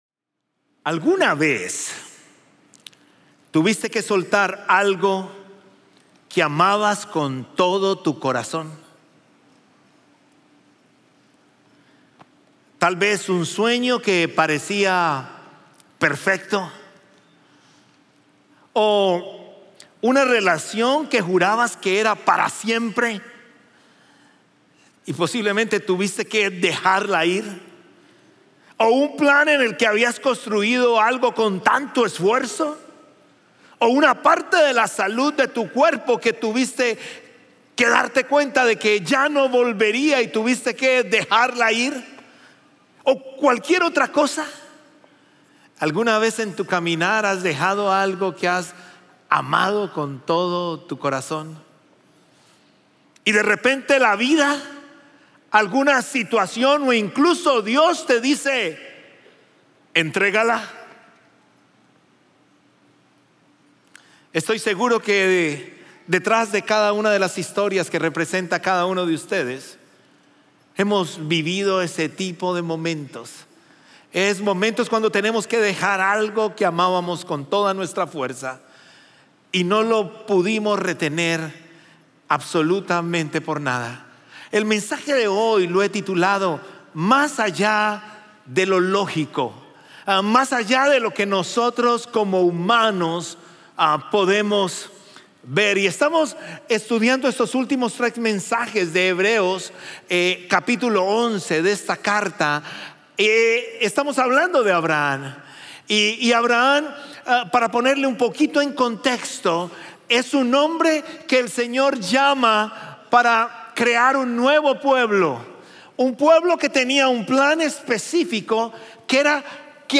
Un mensaje de la serie "Más."